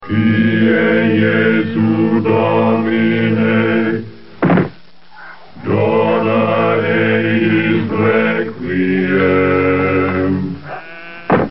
Monks Chant
monks.mp3